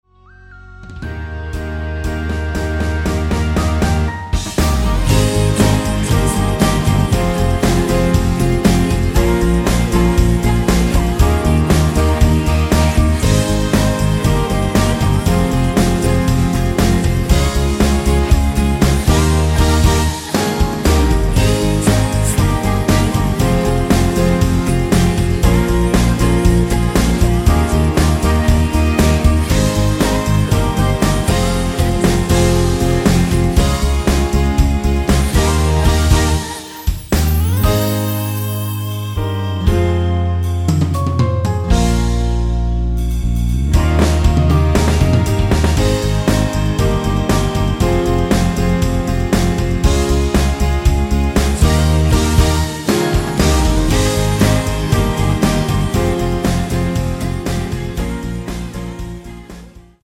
원키 코러스 포함된 MR 입니다.(미리듣기 참조)
Ab
앞부분30초, 뒷부분30초씩 편집해서 올려 드리고 있습니다.